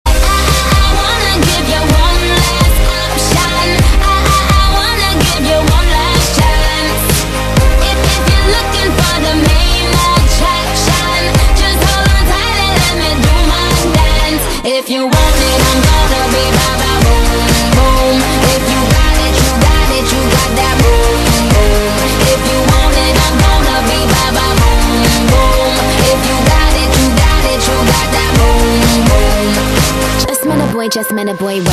M4R铃声, MP3铃声, 欧美歌曲 163 首发日期：2018-05-15 22:24 星期二